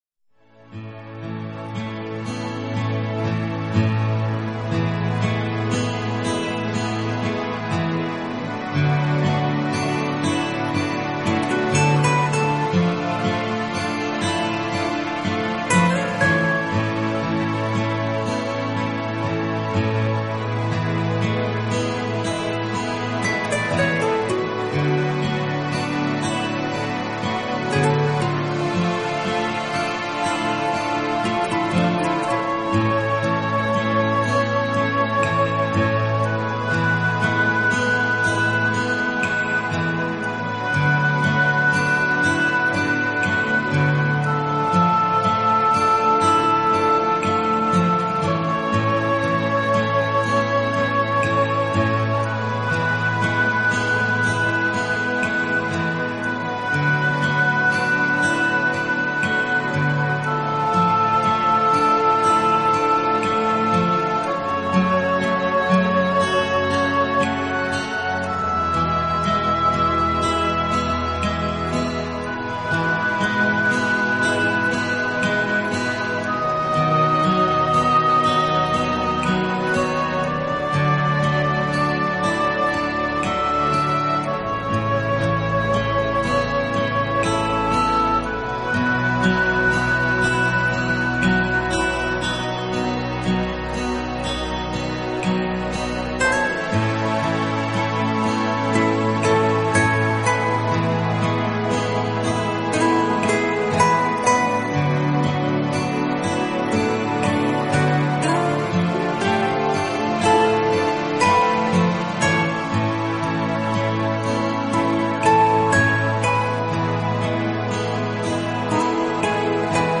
【纯音乐】
最时尚的休闲背景音乐